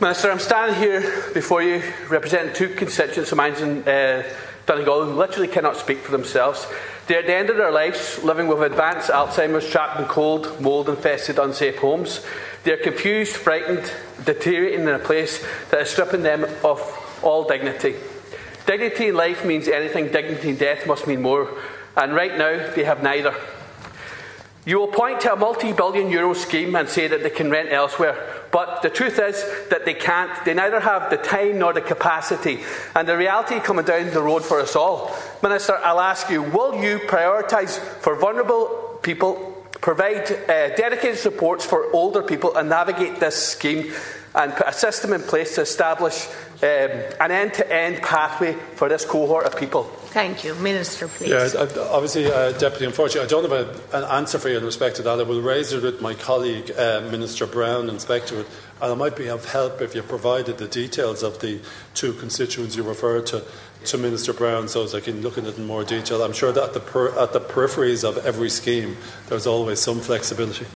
The Dáil has been told action must be taken to support older people affected by defective concrete blocks.